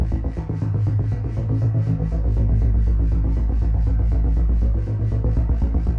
Index of /musicradar/dystopian-drone-samples/Tempo Loops/120bpm
DD_TempoDroneE_120-A.wav